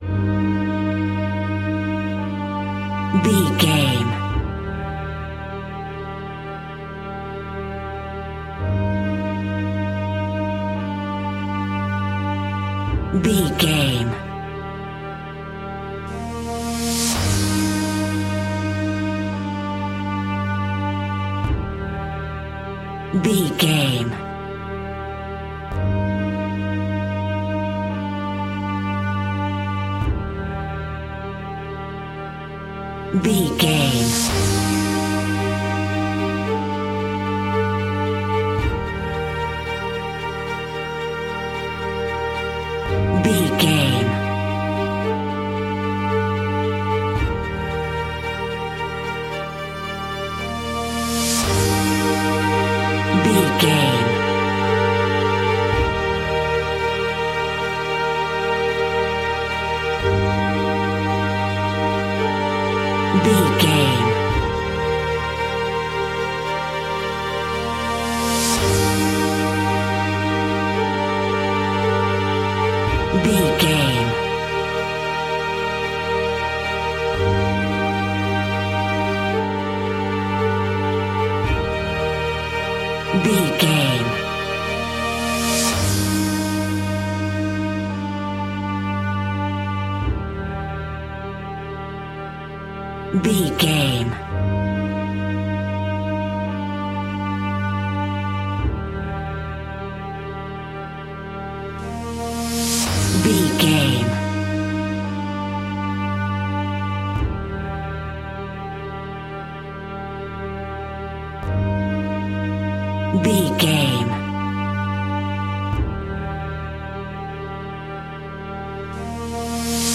Aeolian/Minor
dramatic
epic
strings
percussion
synthesiser
brass
violin
cello
double bass